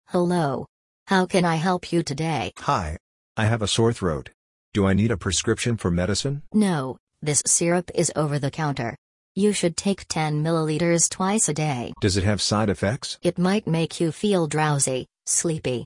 💬 Conversation Practice
Conversation-Practice.mp3